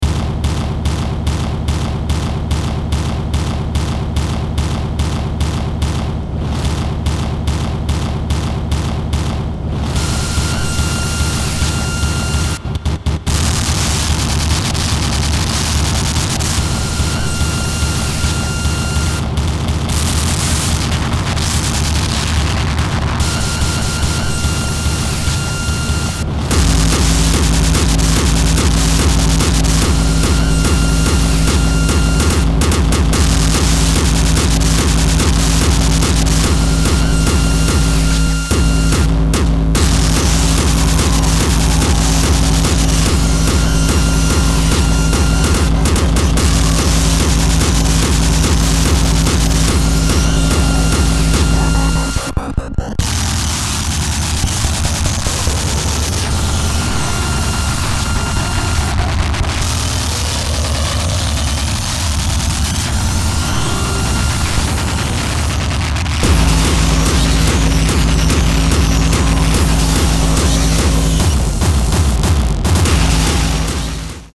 145 BPM